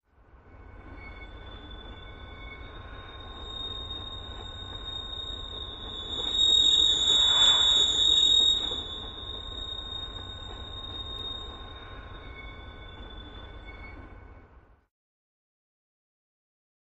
Train Braking, Distant, Forwards Backwards